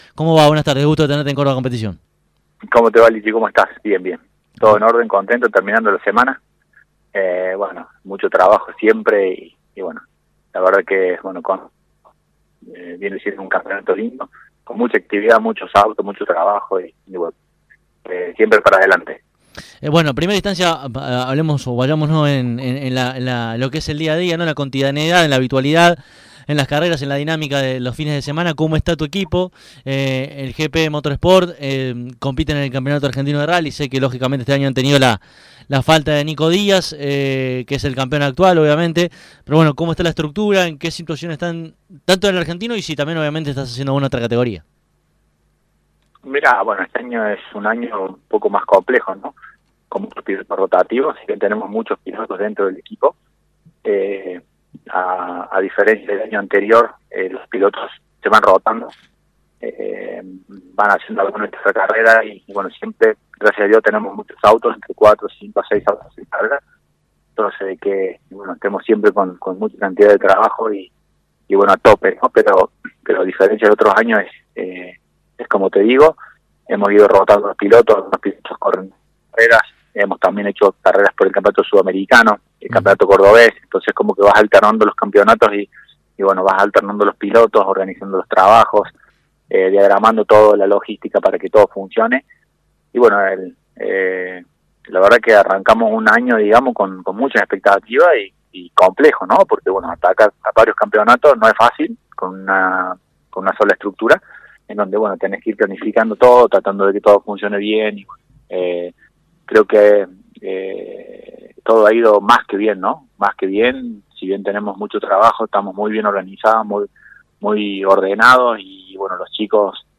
De manera analítica, reflexiva, ecuánime, Gabriel Pozzo cerró la semana hablando en CÓRDOBA COMPETICIÓN.
Escuchá esta interesante entrevista, de manera completa, aquí debajo: